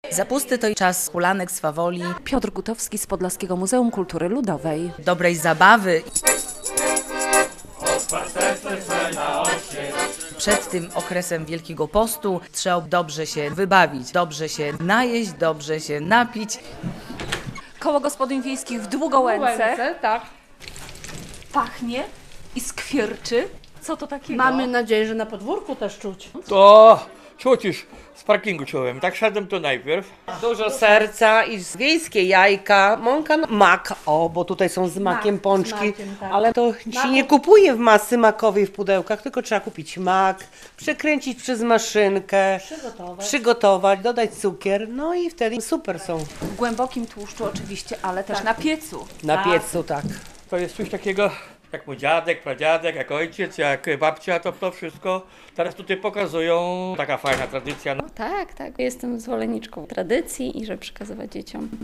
Zapusty w skansenie w Wasilkowie